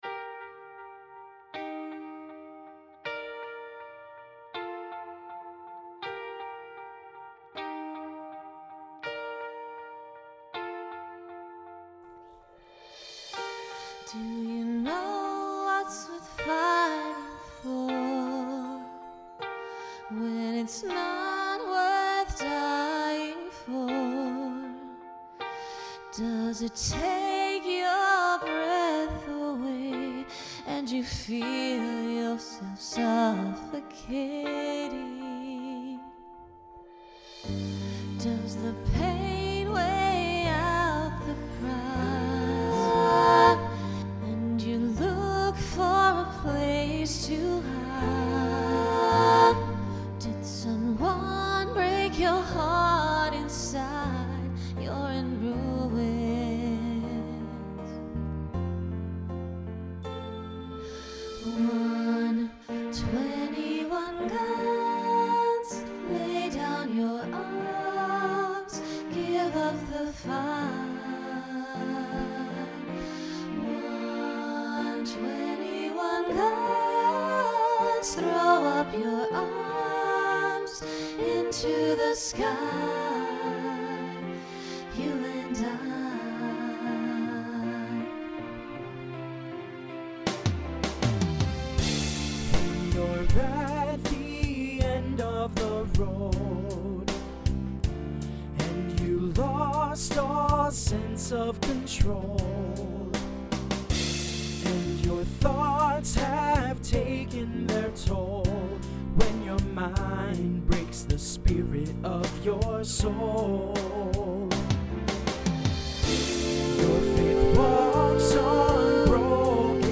SATB Instrumental combo
Broadway/Film , Rock Decade 2000s Show Function Ballad